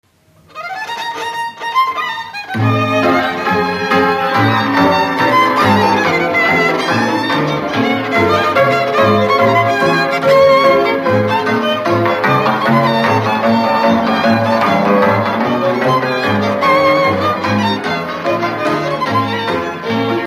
Dallampélda: Hangszeres felvétel
Alföld - Szatmár vm. - Rozsály
hegedű
cimbalom
kontra
bőgő
Műfaj: Lassú csárdás
Stílus: 1.1. Ereszkedő kvintváltó pentaton dallamok